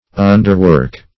Underwork \Un"der*work`\, n.
Underwork \Un`der*work"\, v. t. [imp. & p. p. Underworkedor